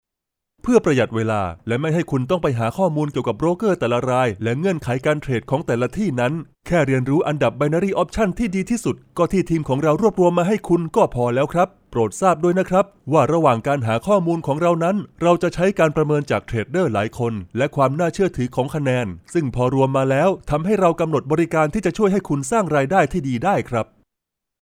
特点：轻快活力 大气浑厚 稳重磁性 激情力度 成熟厚重
• 泰语男女样音